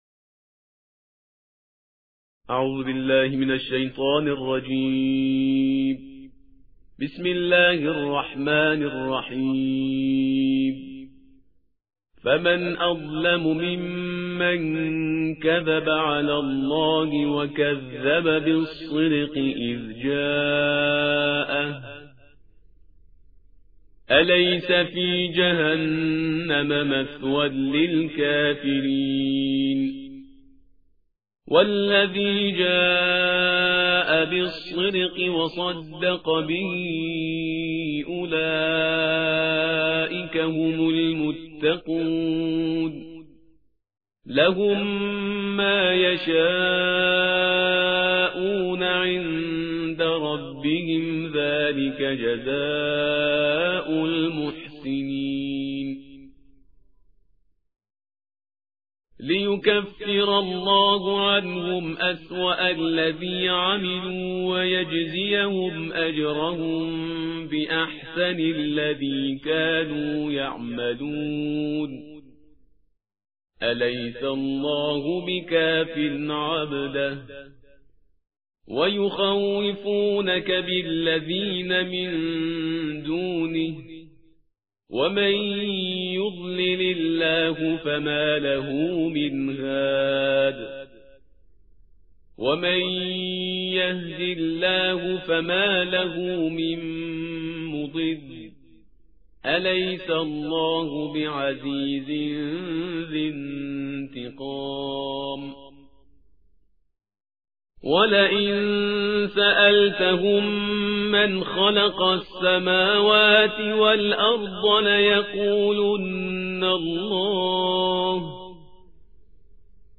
ترتیل جزءبیست و چهار قرآن کریم/استاد پرهیزگار